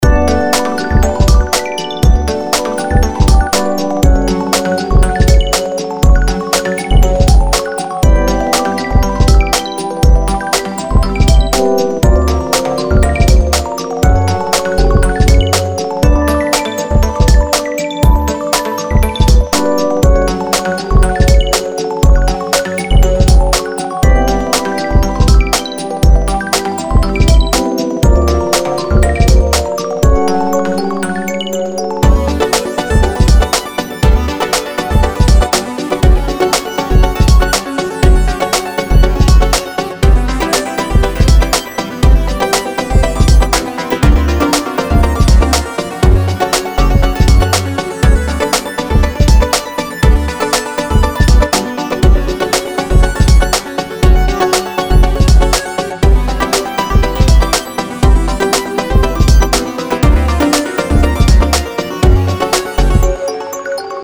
Soundtrack　 BPM:120　 UNSET
ループ KAWAII 楽しい